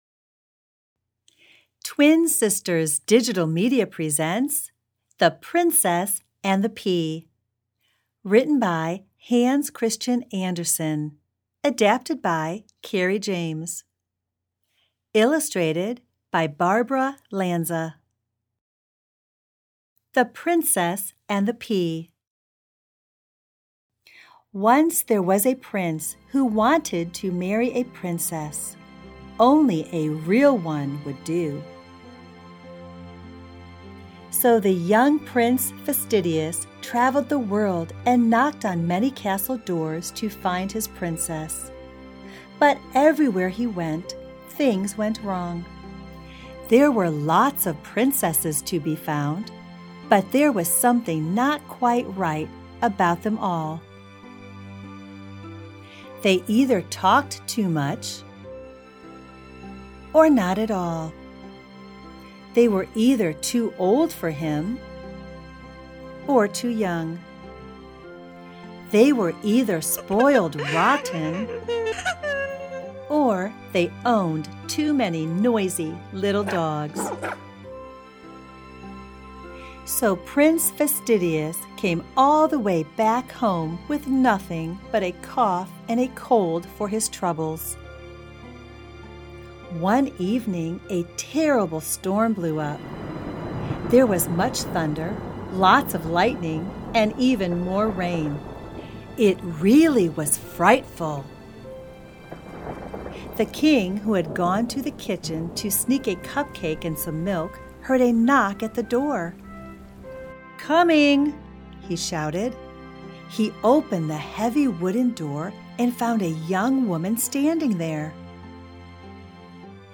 Reading The Princess and the Pea